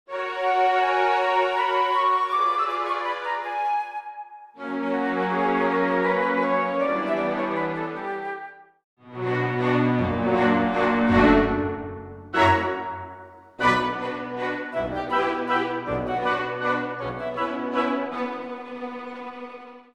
full orchestral accompaniment